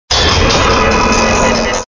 Cri_0486_DP.ogg (Taille du fichier : 20 kio, type MIME : application/ogg)
Cri de Regigigas dans Pokémon Diamant et Perle.